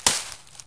hit_wood3.ogg